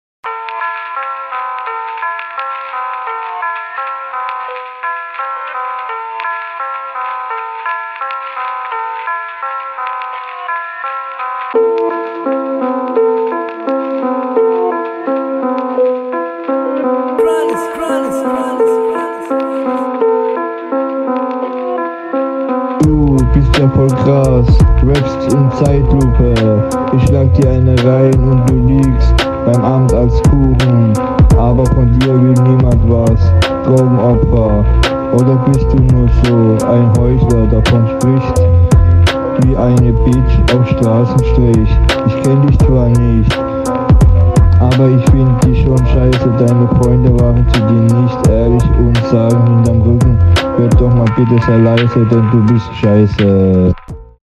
Flow, sorry Bro aber der ist quasi nicht vorhanden.